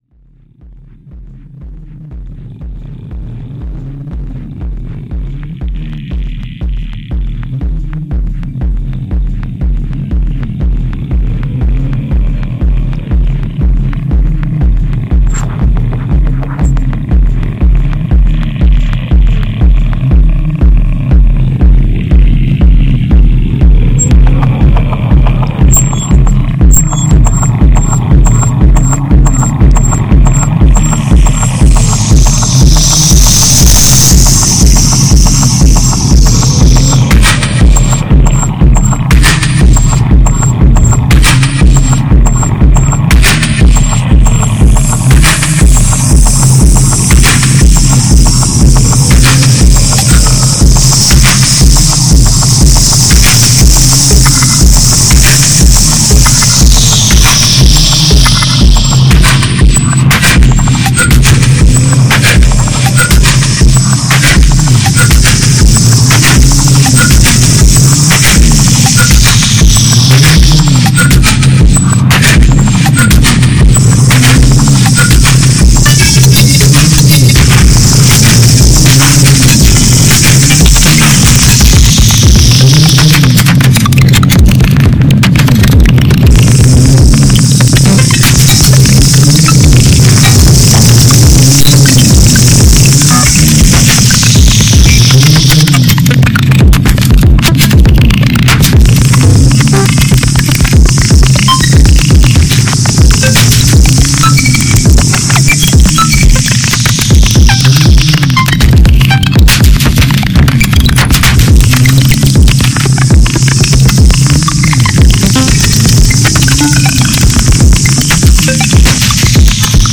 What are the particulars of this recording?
ipad, mic, broken laptop, bad mixer, bad mixing, pretty fuzzy after all